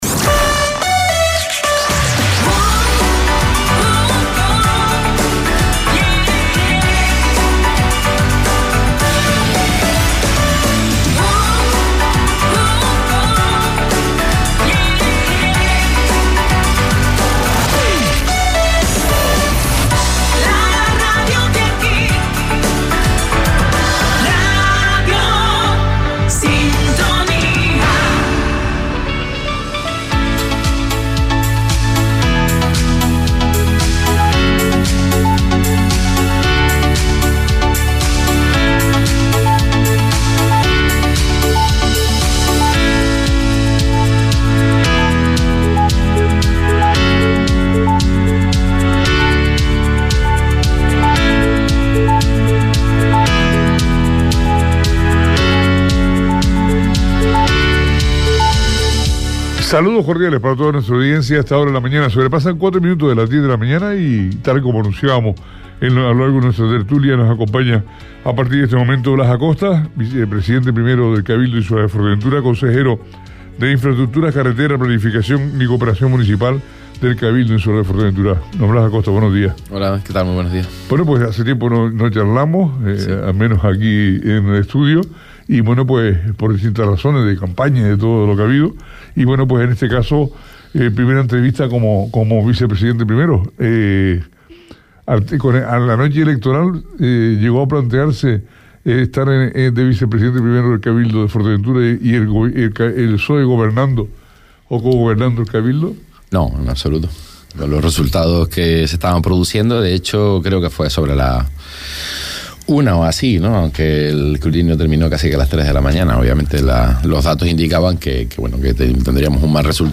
Entrevista a Blas Acosta, consejero de Infraestructuras, Carreteras, Planificación y Cooperación Municipal - 04.07.23 - Radio Sintonía
Entrevistas